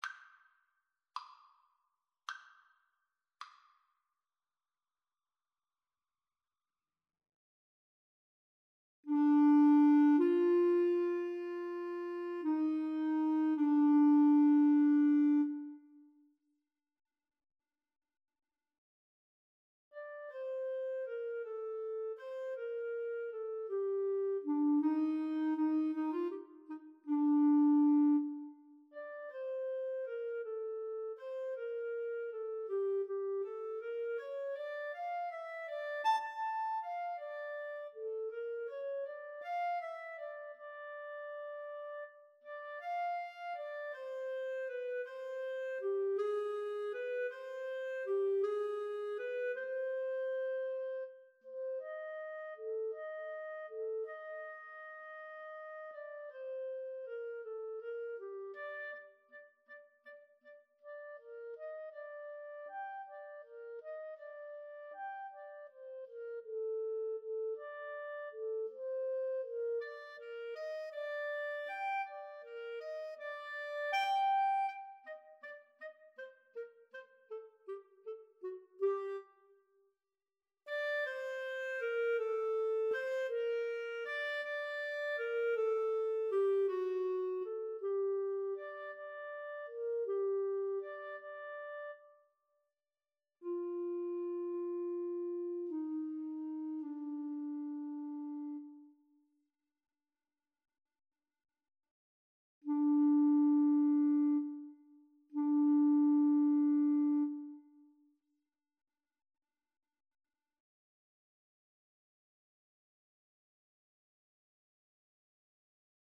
6/8 (View more 6/8 Music)
Andante sostenuto
Classical (View more Classical Clarinet-Cello Duet Music)